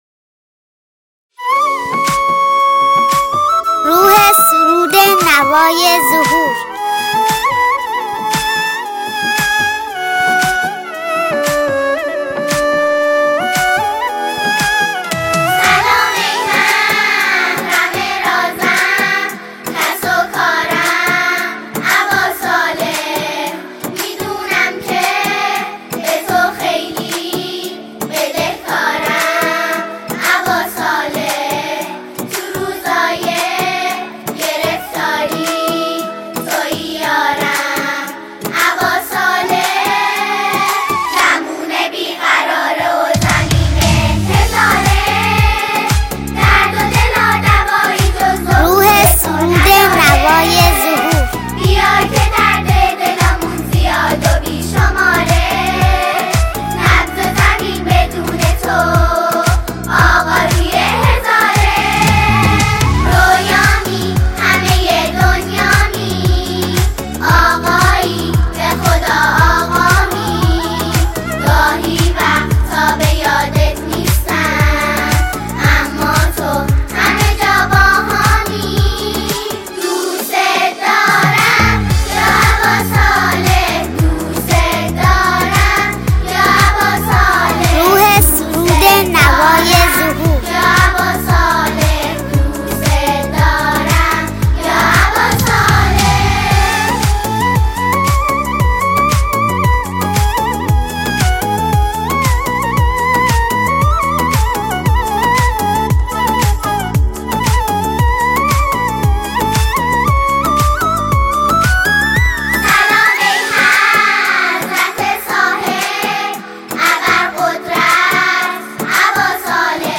زمزمه‌ای آرام، صمیمی و سرشار از دلتنگی
ژانر: سرود